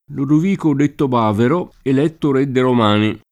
bavaro [b#varo] etn. — sinon., secondo i casi, di baiuvaro e di bavarese — fior. ant. bavero [b#vero]: Lodovico detto Bavero eletto re de’ Romani [